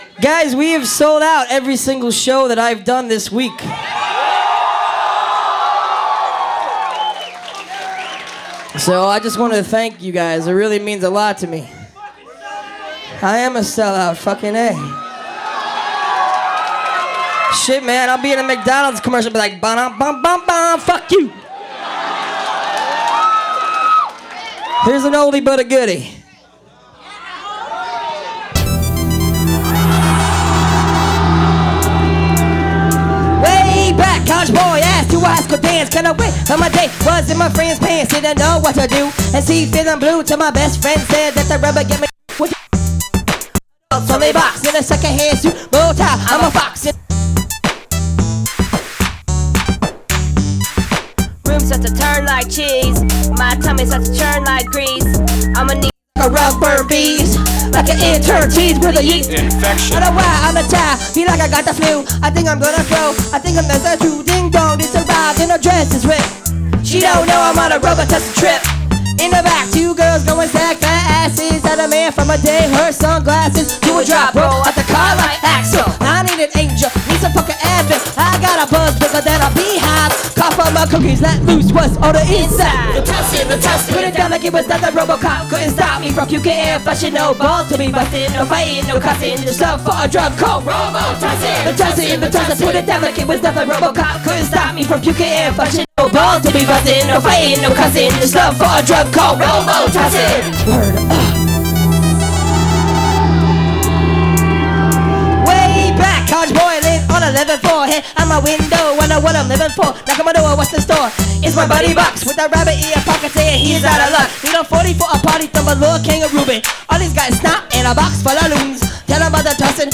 houston, tx at mary janes fat cats on february 6th 2005
soundboard feed